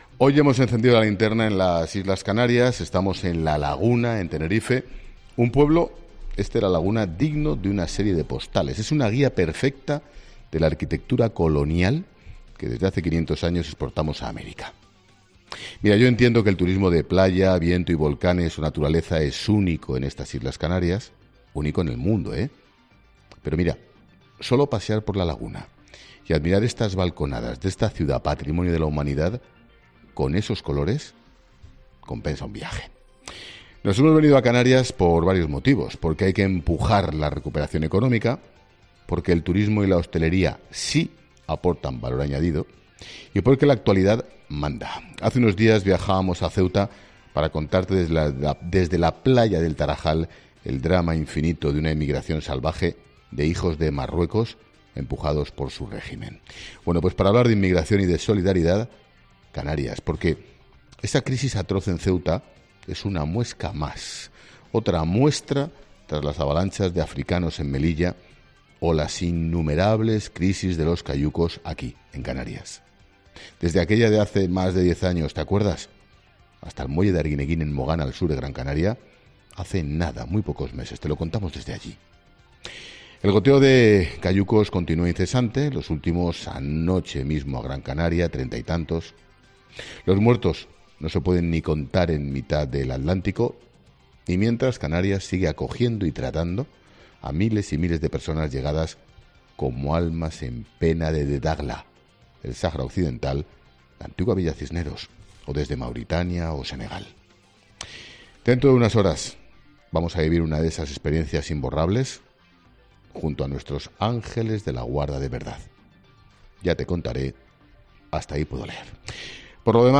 Monólogo de Expósito
El director de 'La Linterna', Ángel Expósito, analiza las principales claves del día desde Tenerife